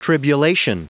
Prononciation du mot tribulation en anglais (fichier audio)
Prononciation du mot : tribulation